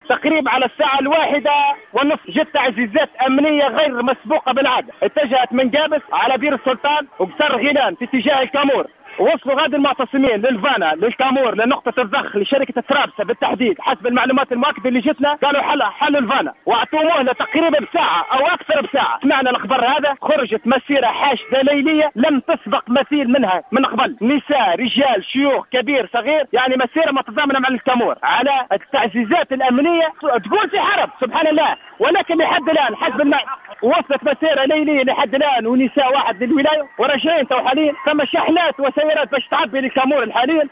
أكد محتج من معتصمي الكامور في تصريح للجوهرة "اف ام" منذ قليل أن الإحتجاجات عادت من جديد بعد التعزيزات الامنية التي تم اقرارها بعد اجتماع أمني بين وزراء الدفاع والداخلية في اتجاه الكامور .
أحد المحتجين